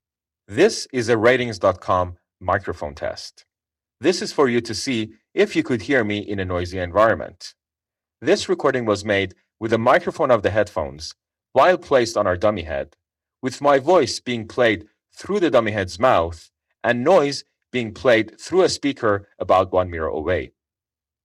Recorded Speech
When connected via the wireless transmitter, your voice sounds clear and natural.
a recording of the mic with the headphones connected via the wireless transmitter.